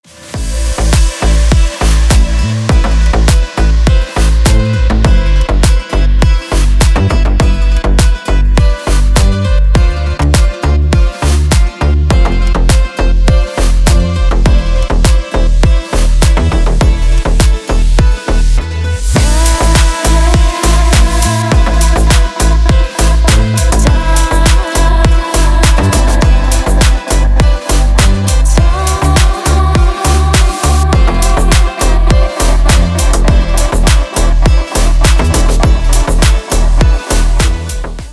• Качество: 320, Stereo
громкие
deep house
восточные мотивы
красивый женский голос
Стиль: deep house